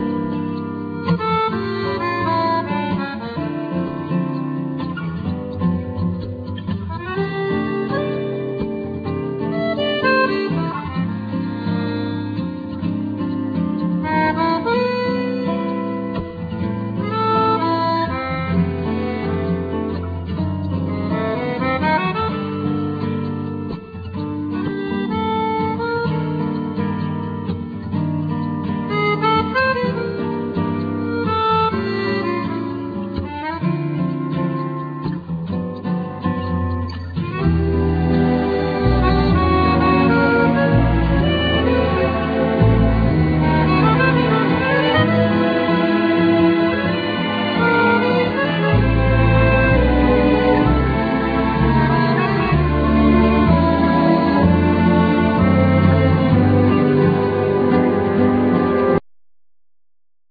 Violin solo
Double bass
Accordeon
Guitar,Banjo,Melodica,Percussions